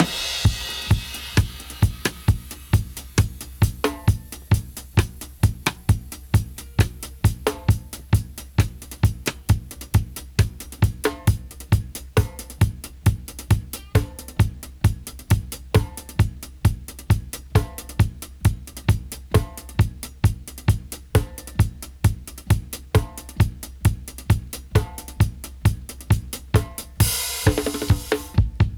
131-DRY-04.wav